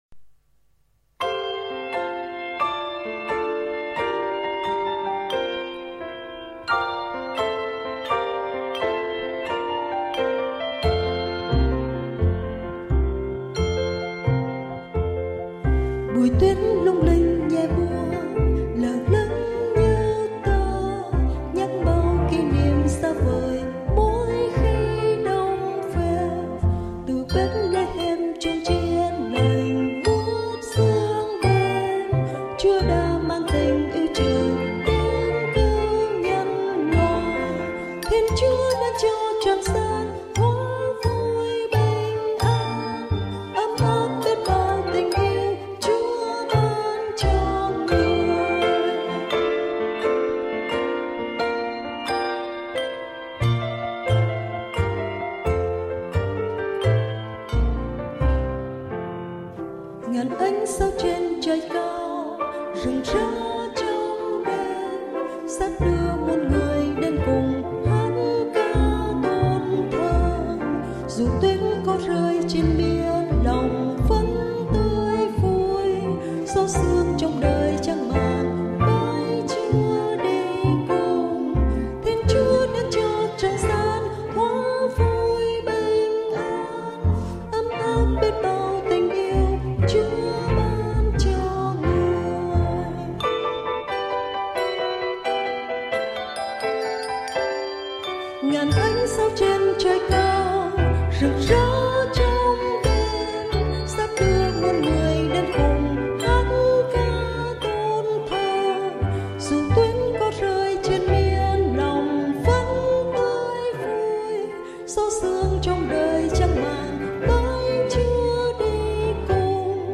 Cổ điệu Irish